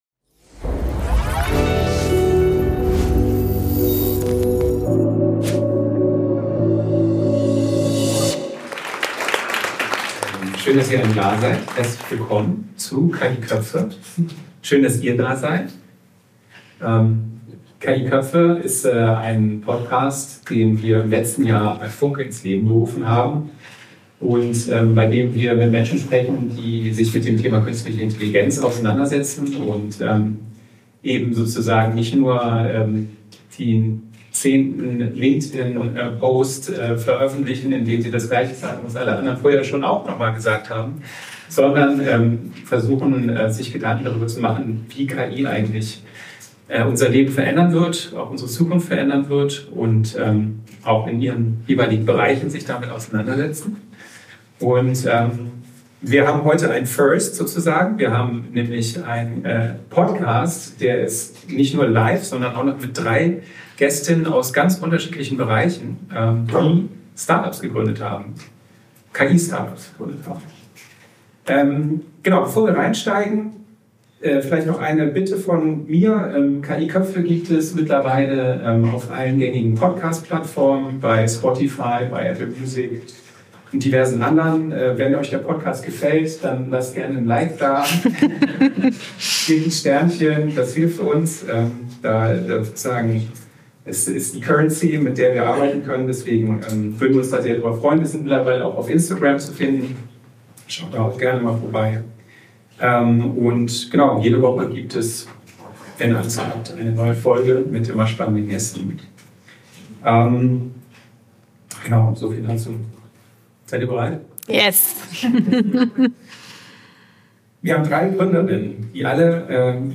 Drei Gründerinnen.
Eine Live-Bühne. Was verbindet Superglue, SceneContext und AI Legal Club – und was können wir alle davon lernen?